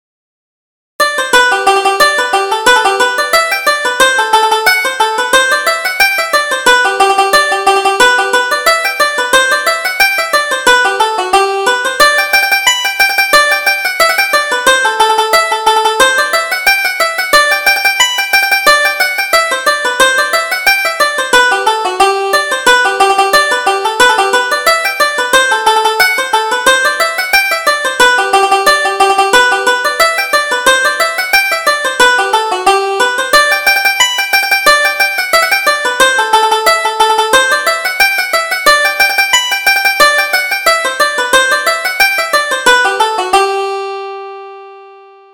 Reel: Clancy's Fancy Reel